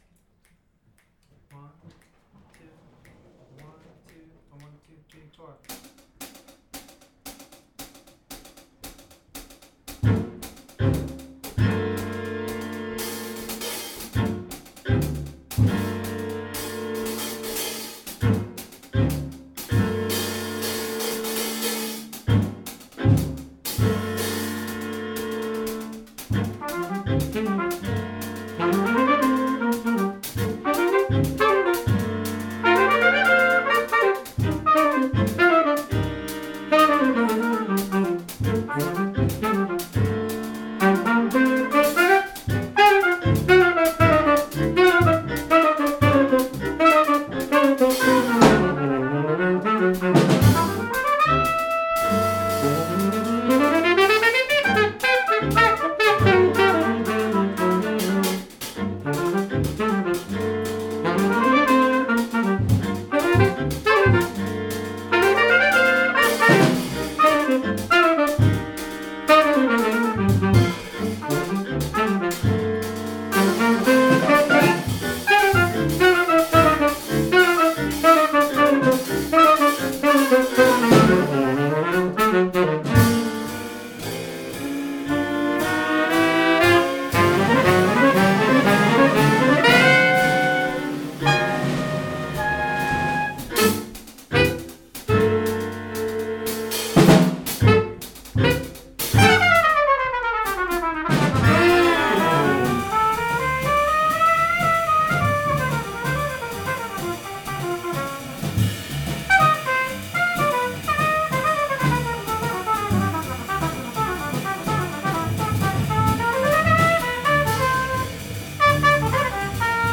Trumpet
Sax
Violin
Viola
Bass
Drums and Vibes